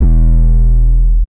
BWB UPGRADE3 DISTOROTION 808 (6).wav